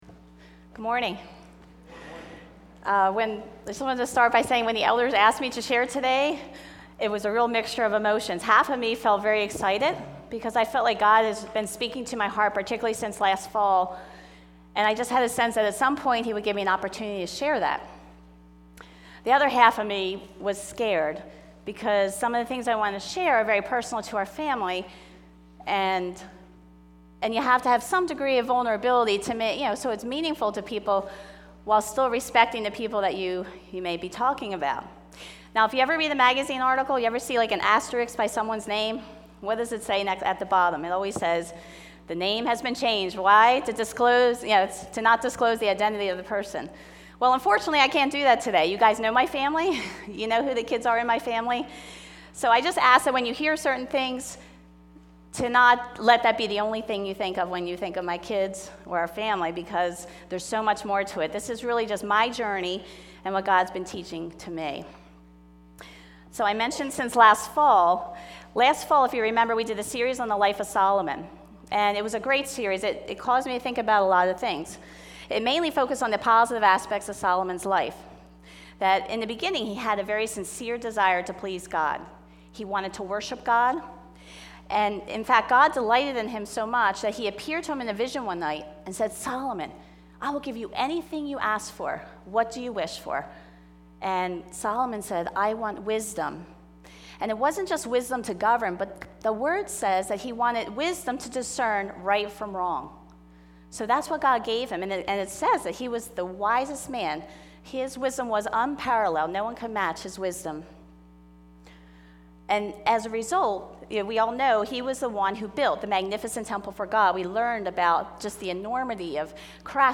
Mother's Day message 2012